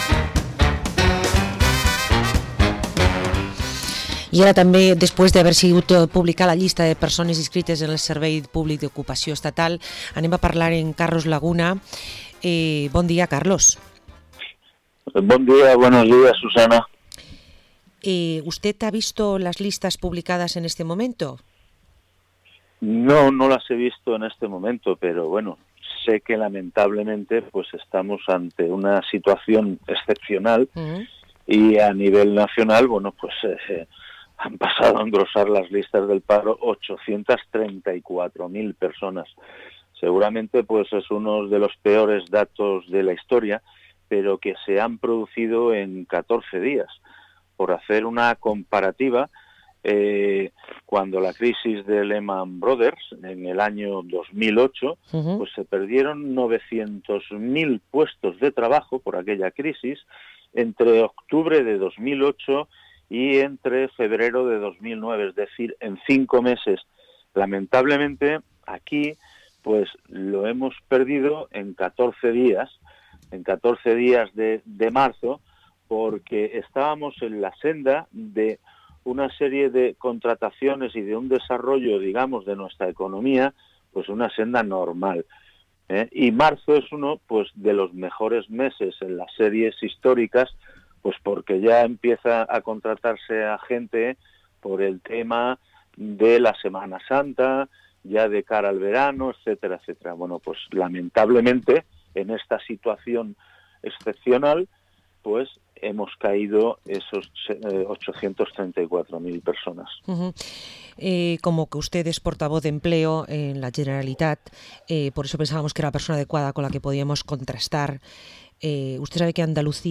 Entrevista al Portavoz de empleo en las Cortes y diputado autonómico del PSPV-PSOE, Carlos Laguna